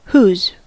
whose: Wikimedia Commons US English Pronunciations
En-us-whose.WAV